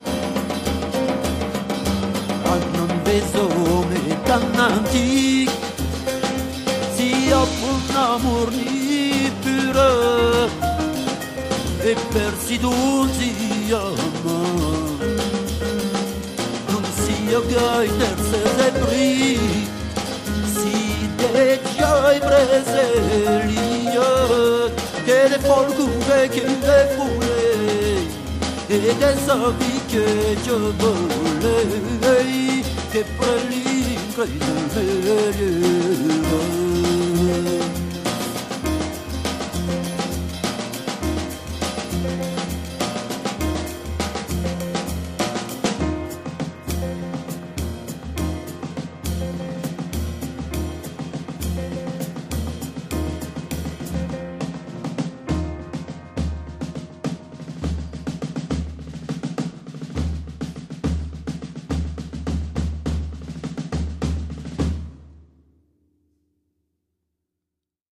cansos dels trobadors /